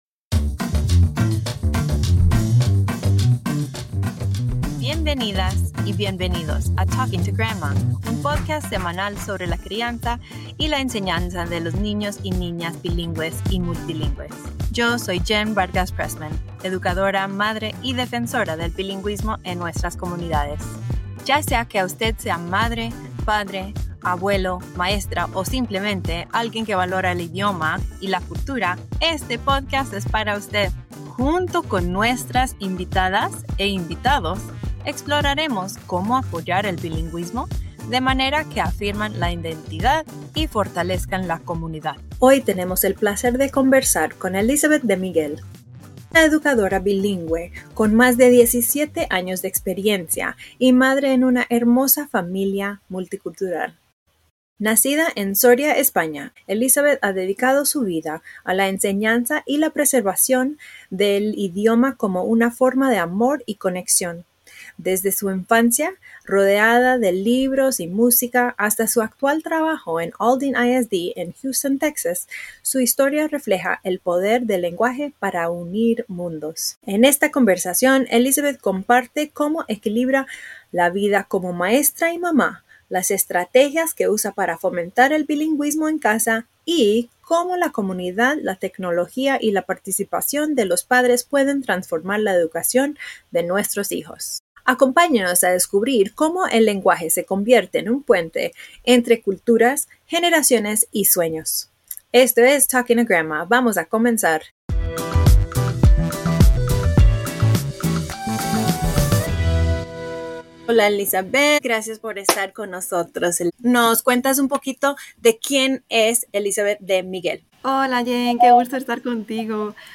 In our conversation